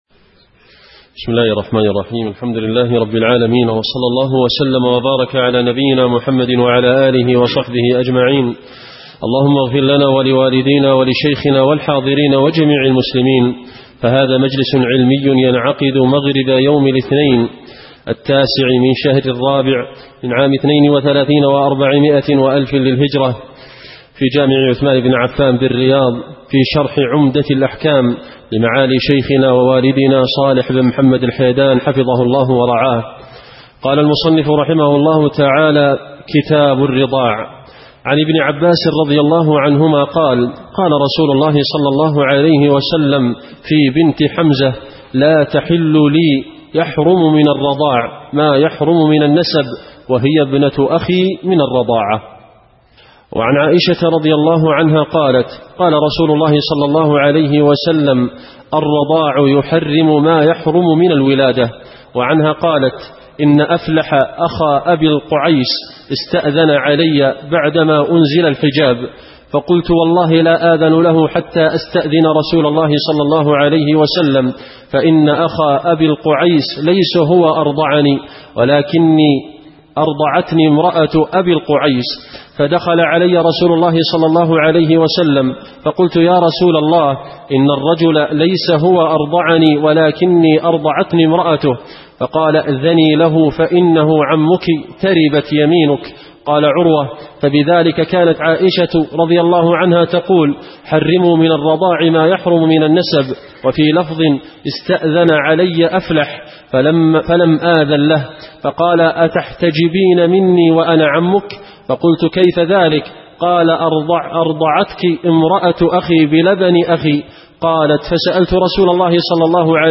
الدرس الخامس والثلاثون من بداية كتاب الرضاع إلى نهاية حديث ابن مسعود (أول ما يقضى بين الناس)